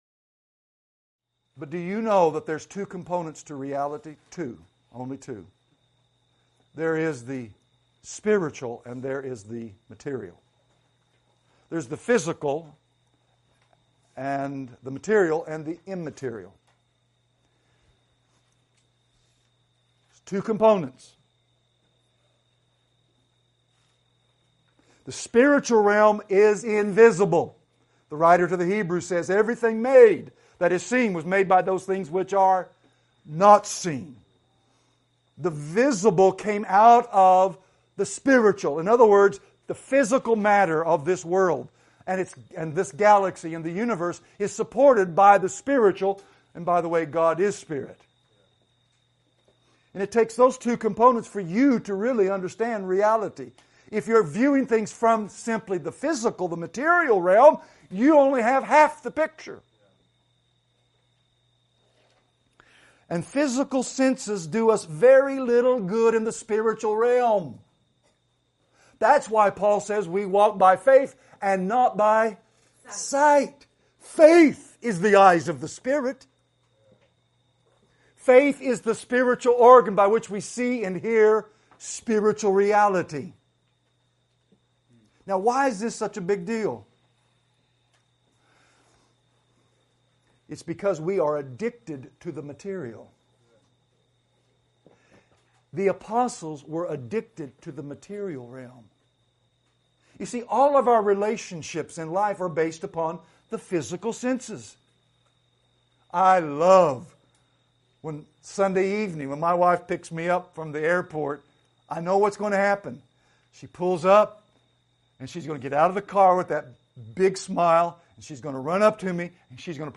Excerpt from the full sermon: Desperate Dependency: Christianity’s Parable (Part 1)